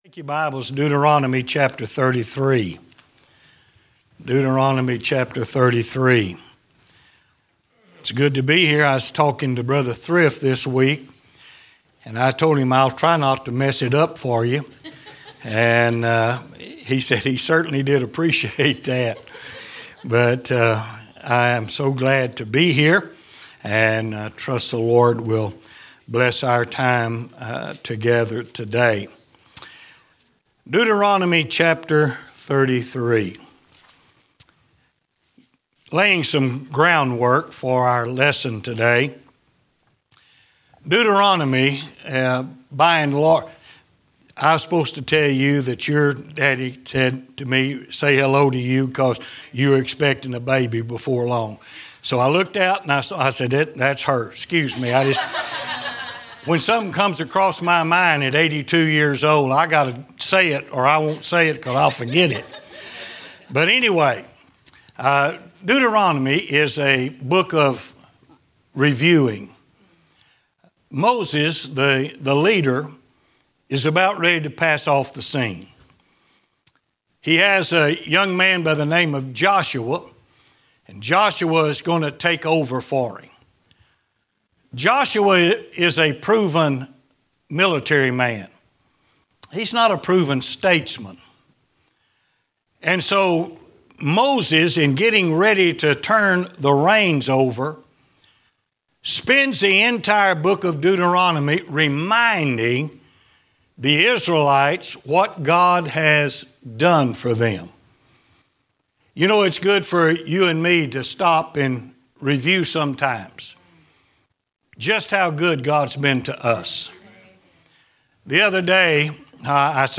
Sermons - Emmanuel Baptist Church